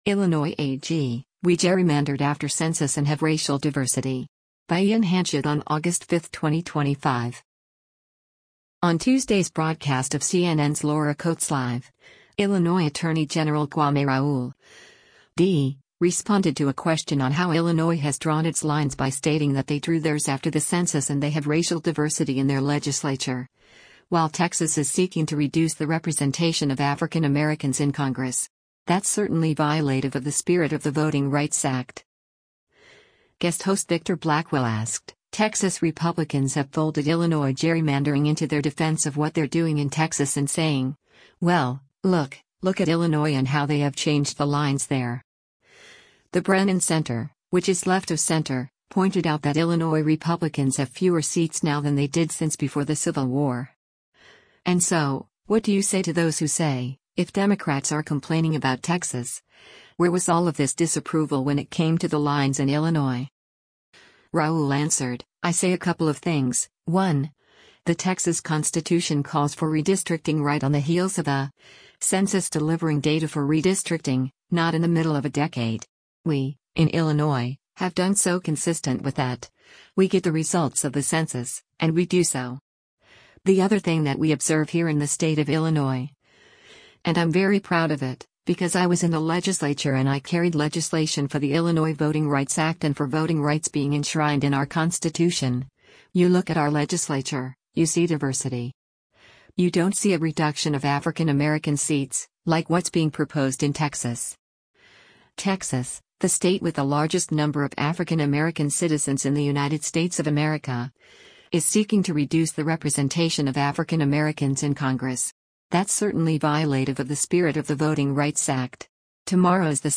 On Tuesday’s broadcast of CNN’s “Laura Coates Live,” Illinois Attorney General Kwame Raoul (D) responded to a question on how Illinois has drawn its lines by stating that they drew theirs after the Census and they have racial diversity in their legislature, while Texas “is seeking to reduce the representation of African Americans in Congress. That’s certainly violative of the spirit of the Voting Rights Act.”